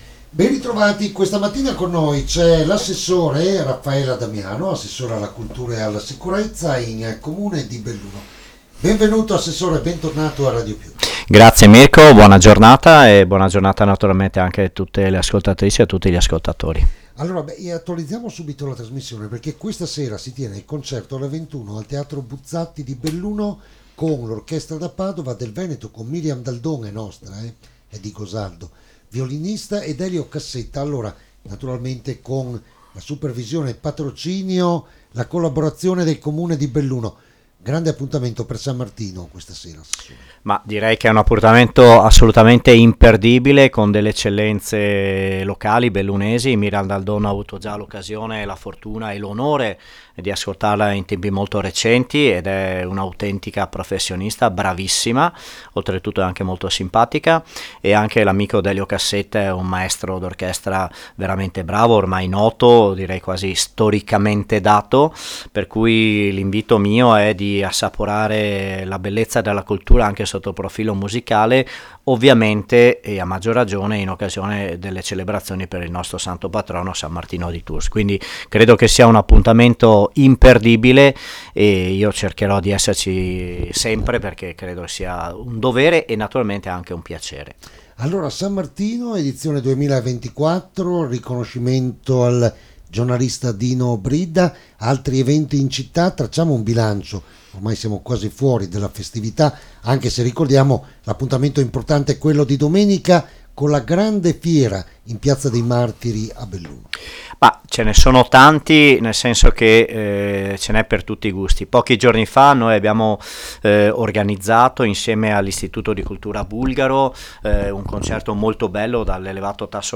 L’INTERVISTA, OSPITE L’ASSESSORE ALLA CULTURA E SICUREZZA RAFFAELE ADDAMIANO